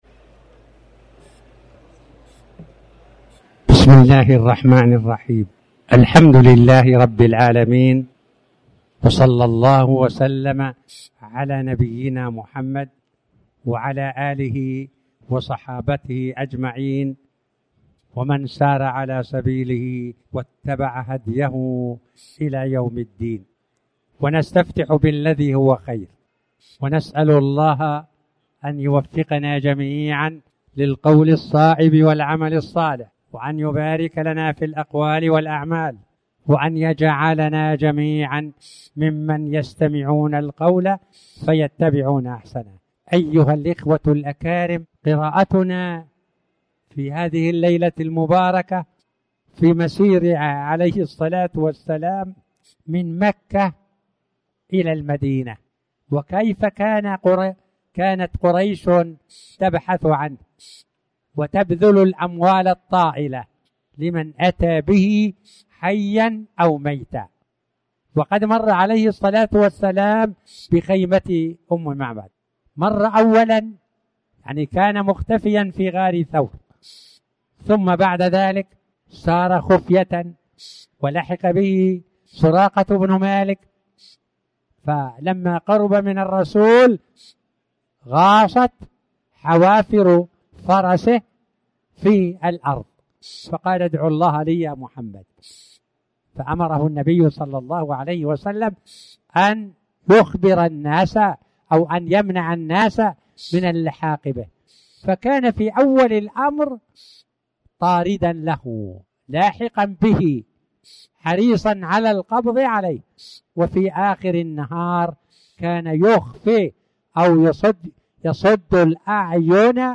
تاريخ النشر ١٩ شوال ١٤٣٩ هـ المكان: المسجد الحرام الشيخ